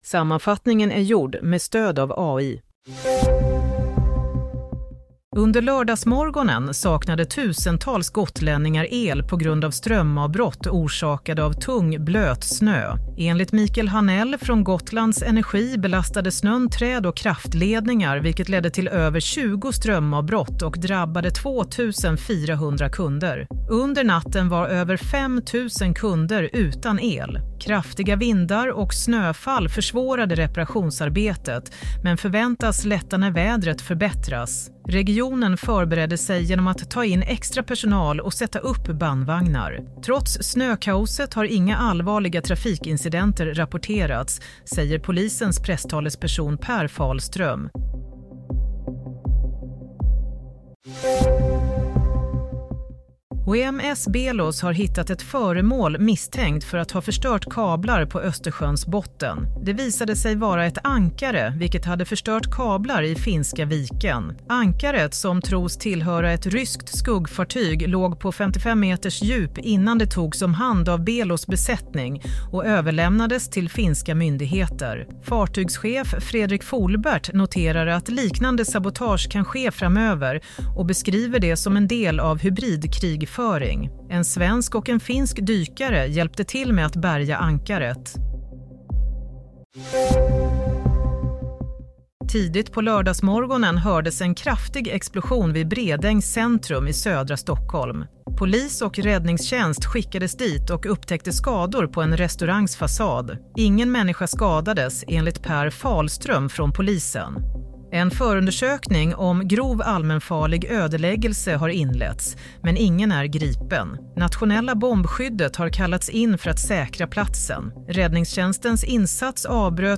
Nyhetssammanfattning - 11 januari 07:00